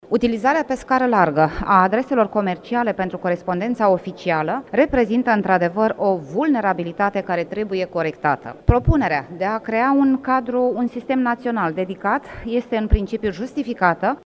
Senatoarea PSD Doina Federovici: „Propunerea de a crea un sistem național dedicat este, în principiu, justificată”